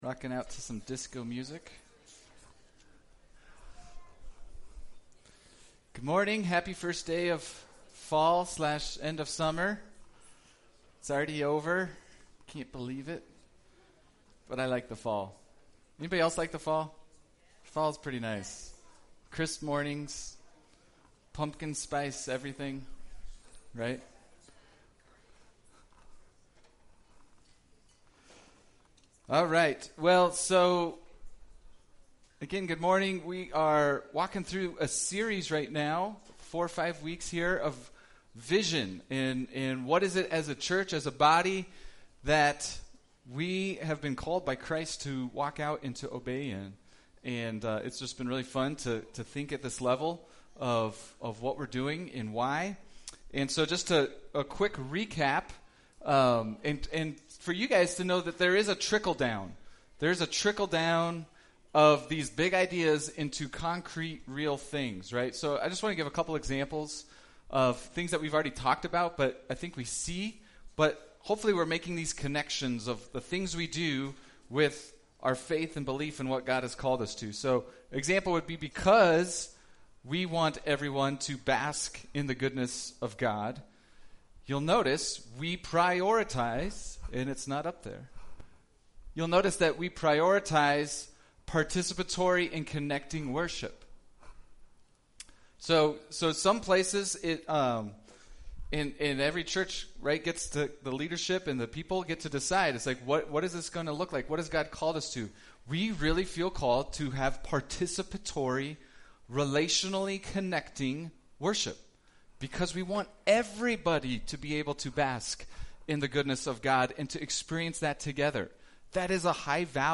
BG Archives Service Type: Sunday Speaker